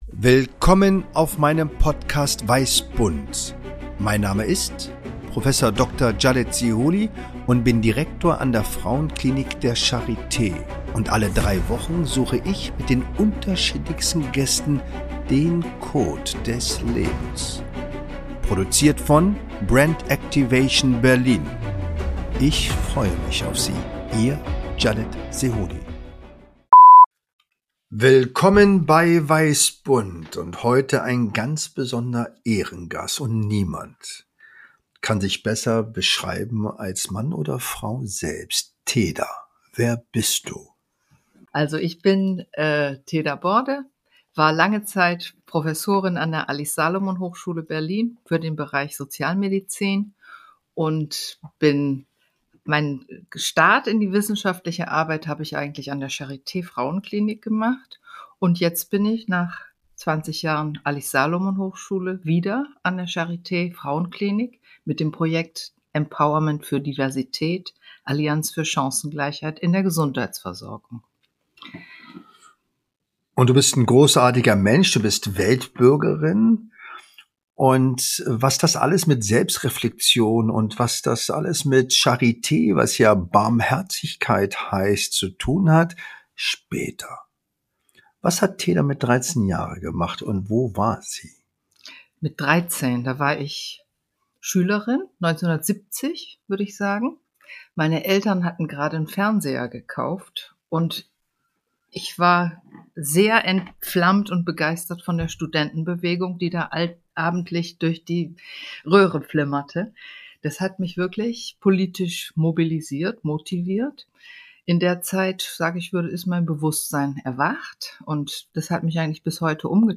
Spontan, intuitiv, ohne Skript, Improvisation pur! Authentisch, ehrlich, direkt!